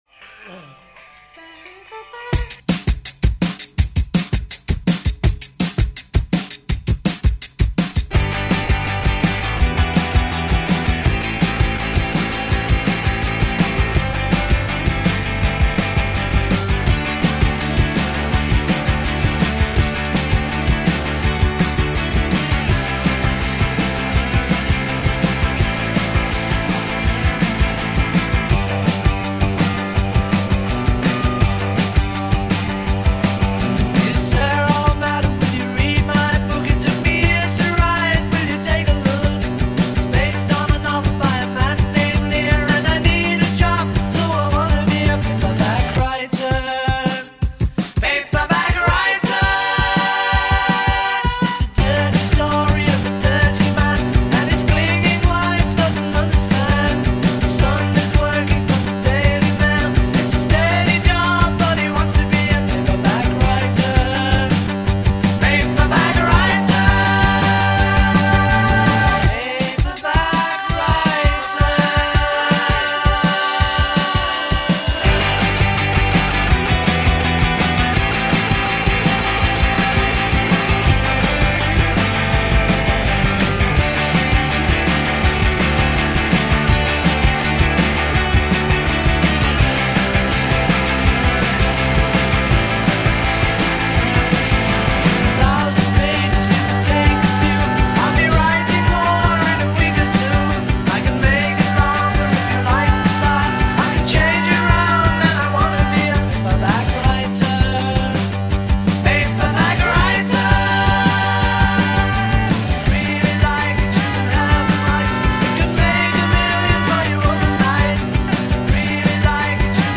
¡puro estilo rock indie!